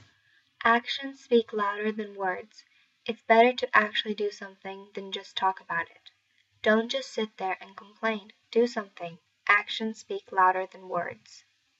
これは、行動は言葉よりも雄弁という諺で、日本語でこれに近いのは、「不言実行」という言葉が思い浮かびます。 英語ネイティブによる発音は下記のリンクをクリックしてください。